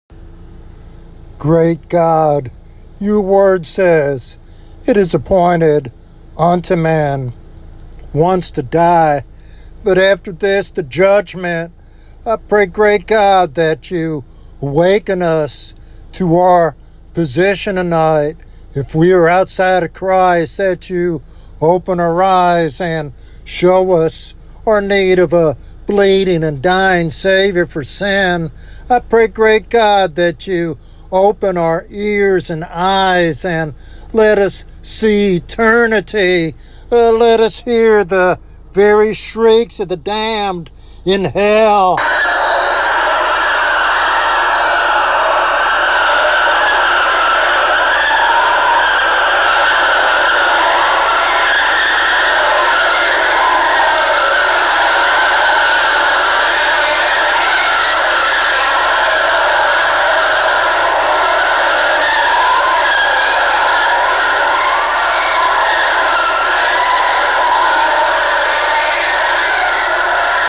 Why does the speaker emphasize hearing the shrieks of the damned?